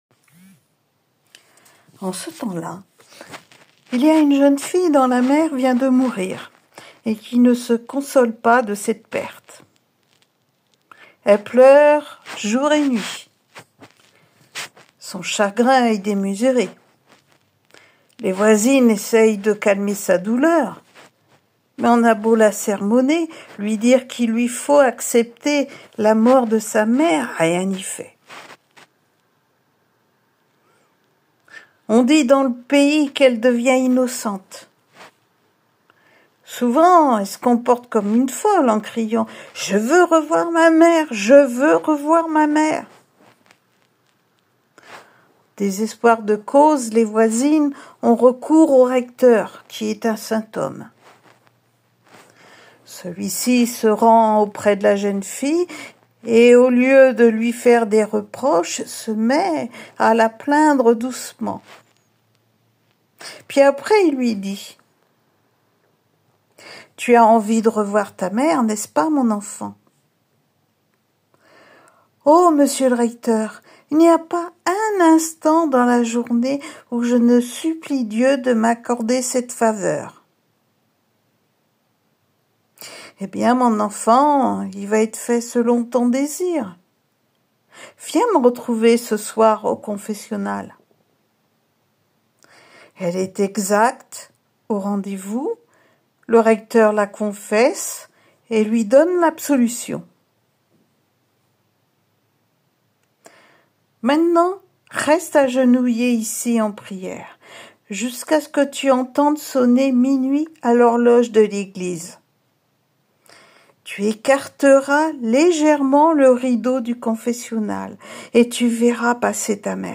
L’association Histoires de mots vous propose 3 contes audio pour une immersion totale dans les paysages vécus et contés d’Erdeven.